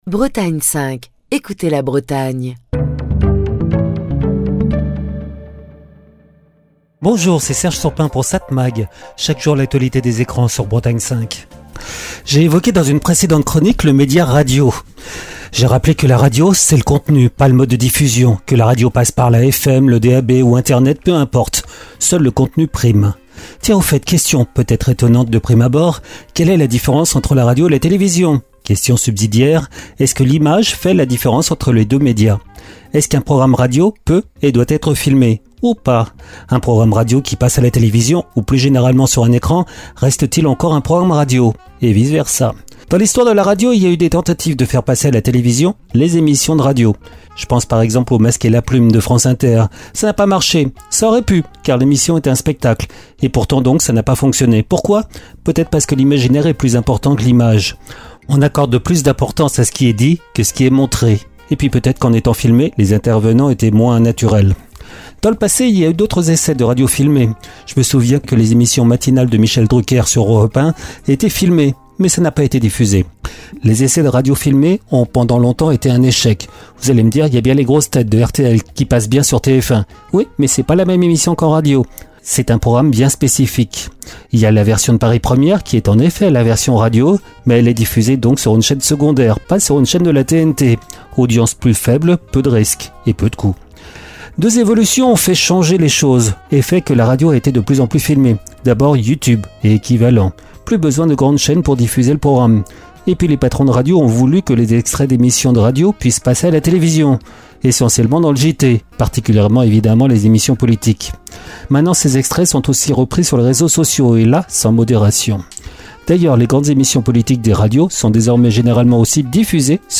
Chronique du 25 mars 2025. La question a longtemps divisé les professionnels de l'audiovisuel : Faut-il filmer la radio ?